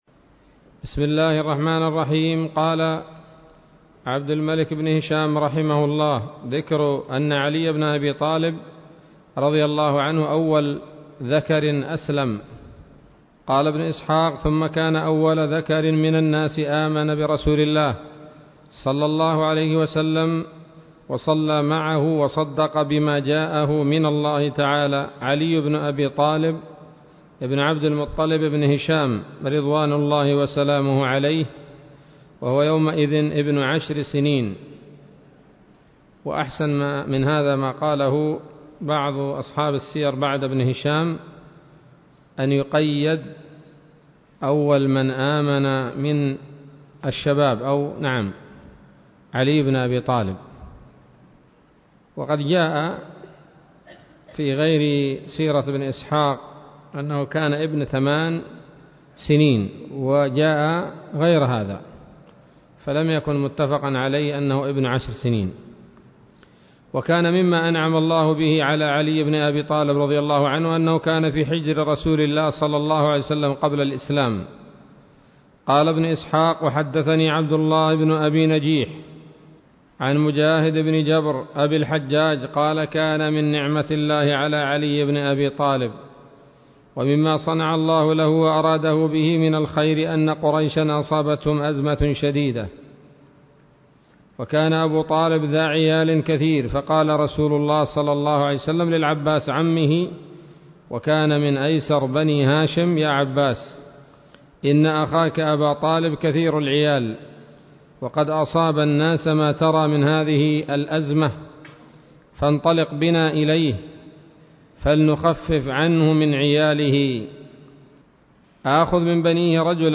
الدرس الخامس والعشرون من التعليق على كتاب السيرة النبوية لابن هشام